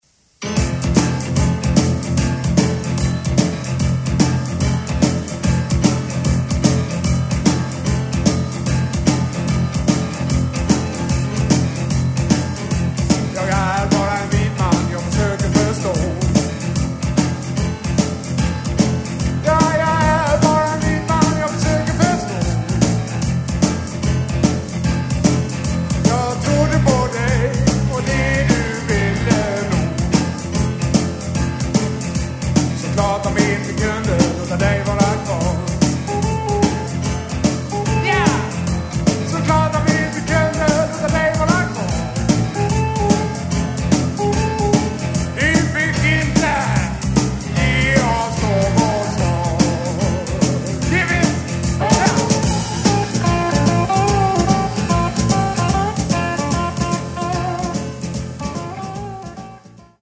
Sång, munspel
Gitarr
Piano
Trummor